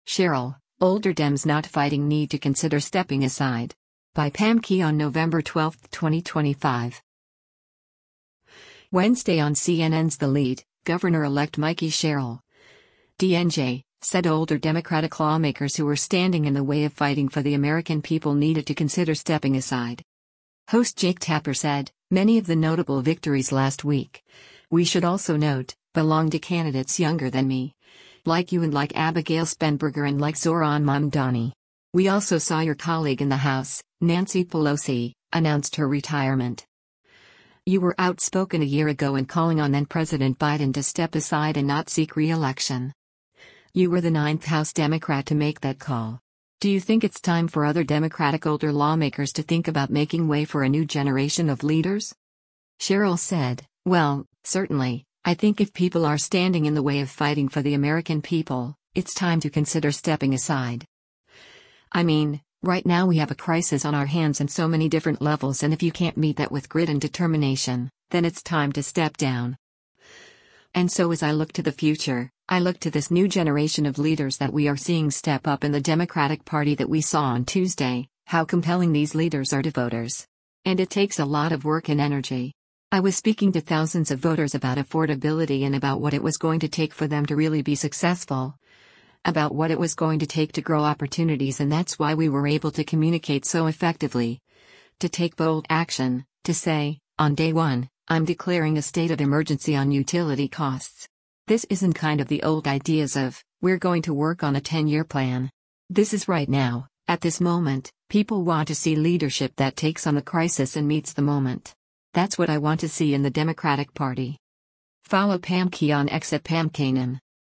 Wednesday on CNN’s “The Lead,” Gov.-elect Mikie Sherrill (D-NJ) said older Democratic lawmakers who were standing in the way of fighting for the American people needed to “consider stepping aside.”